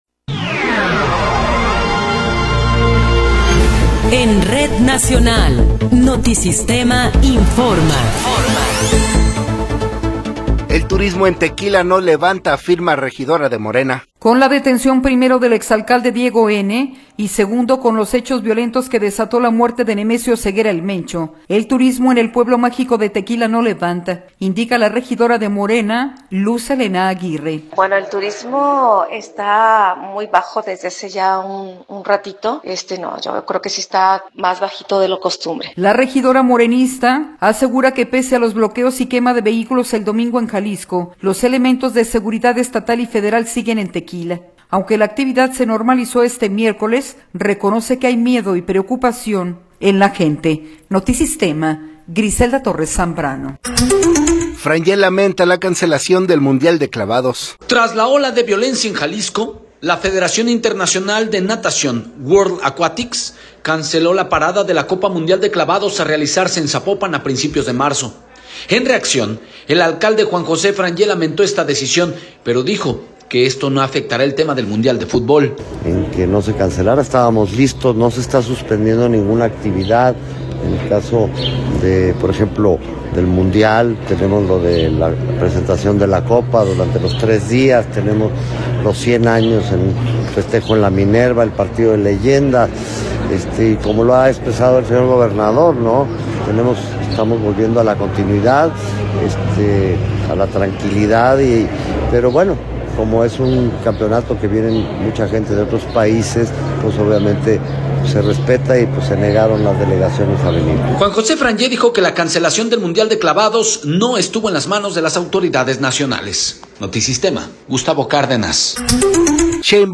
Noticiero 13 hrs. – 26 de Febrero de 2026
Resumen informativo Notisistema, la mejor y más completa información cada hora en la hora.